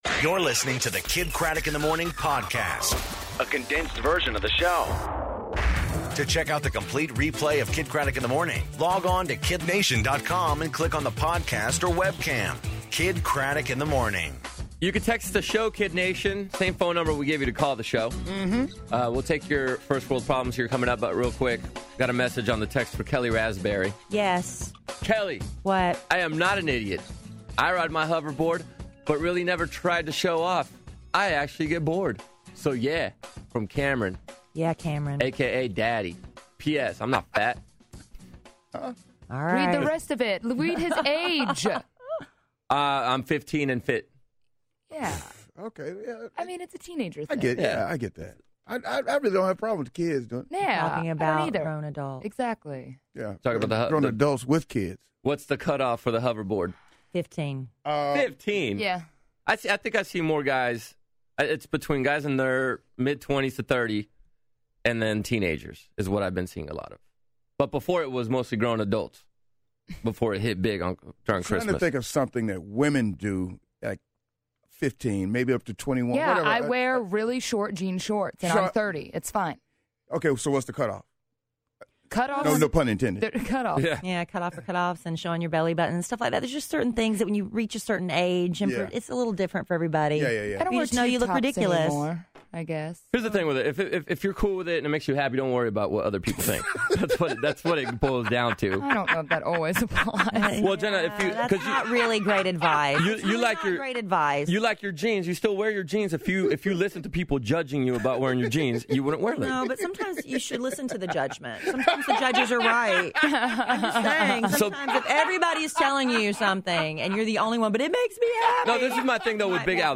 First World Problems, Jelly Bean Roulette , And Jerry Springer In Studio